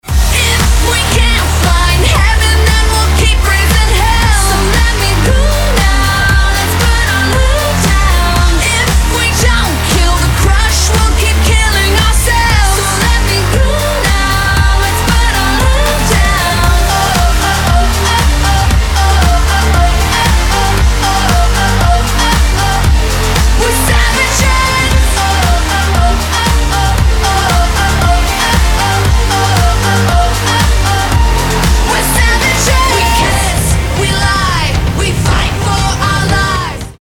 • Качество: 320, Stereo
громкие
женский вокал
Electronic
Pop Rock
alternative